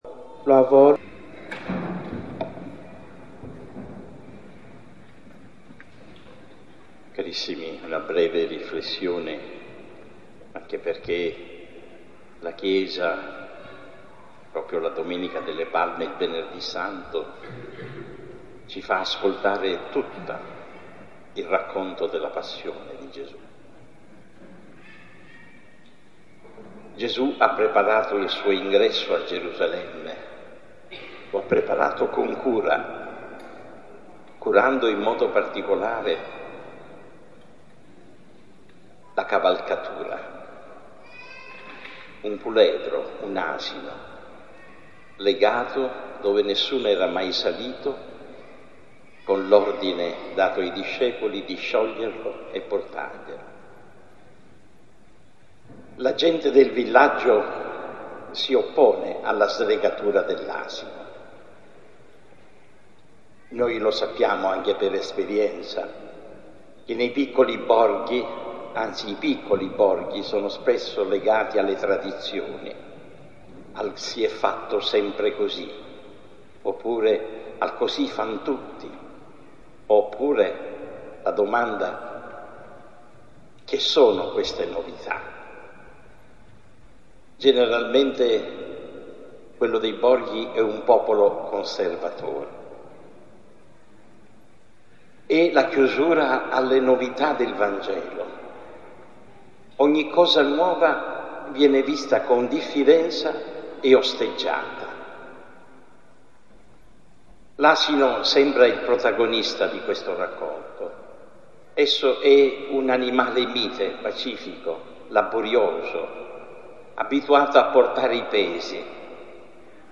Subito dopo ha fatto seguito la Processione verso la Basilica Cattedrale dove è stata celebrata la S. Messa.
AUDIO: OMELIA DEL VESCOVO
Omelia del Vescovo.mp3